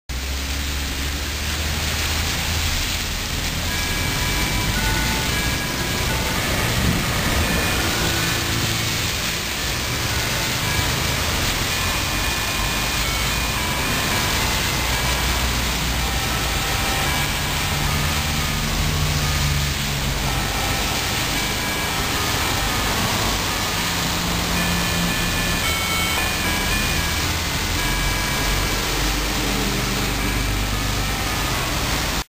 雨の中で、収録中に車が通るたびにシャーという音が入るため実際行きたくないながらも行きましたが、結構２１号以外がありました。
聞いた感じは小糸□号のような感じでした。
通りゃんせは比較的□号に近い音質ですね。けど、微妙に違います。特に1曲目から2曲目にすぐつながる点が□号と大きく異なる点ですね。